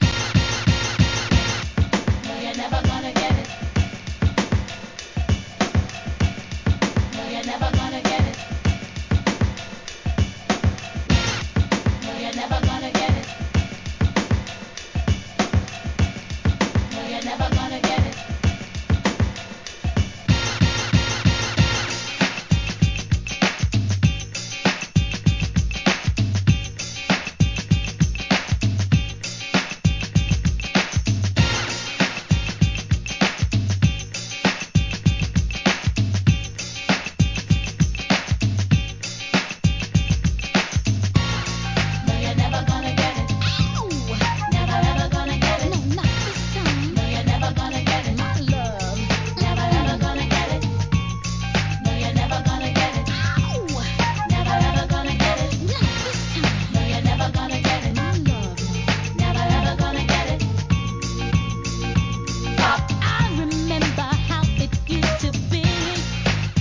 HIP HOP/R&B
FUNKYなビートに気持ちいいフルートとヴォーカルが見事◎